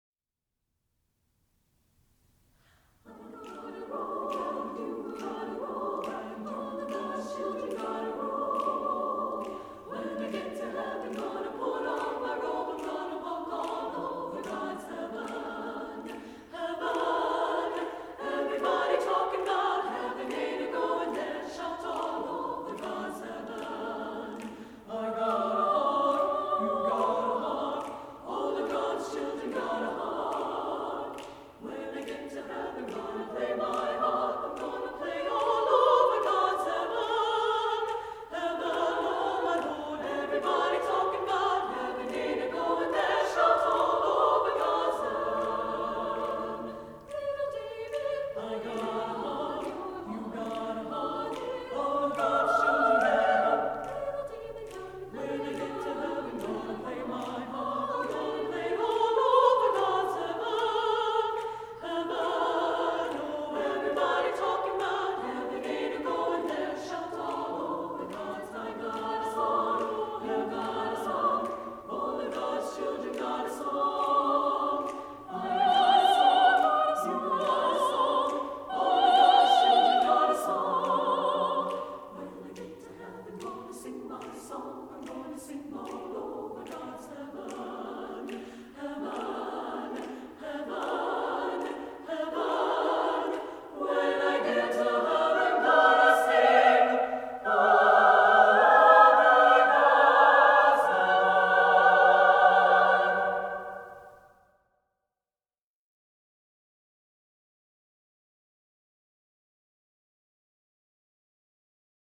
Composer: Spiritual
Voicing: SSAA